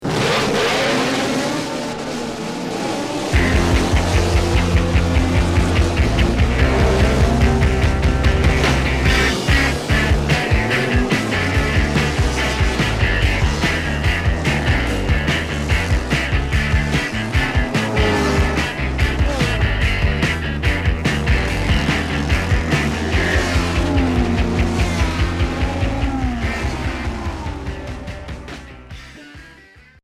Pop Rock Deuxième 45t retour à l'accueil